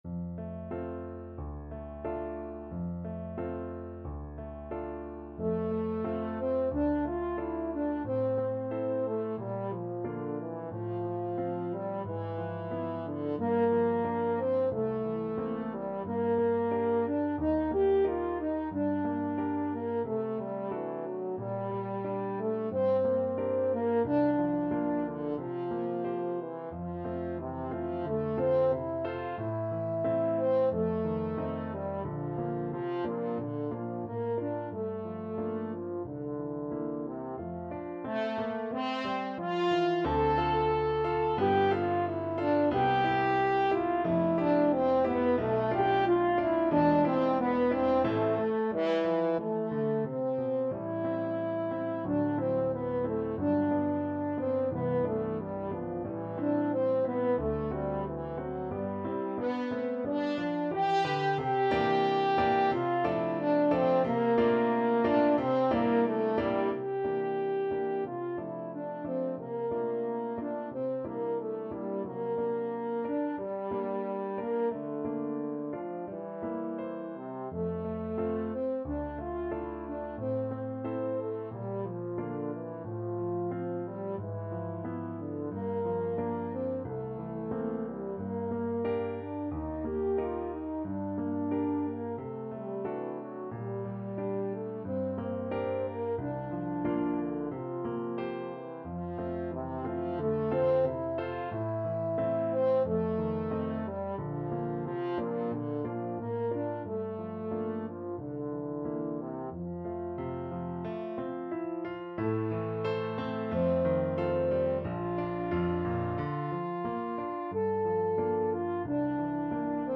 2/4 (View more 2/4 Music)
~ = 100 Allegretto con moto =90
Classical (View more Classical French Horn Music)